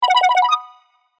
LINEやFacebook、Twitterなどのポップアップ通知、メッセージ通知なのに丁度良いポップアップ音。